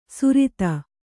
♪ surita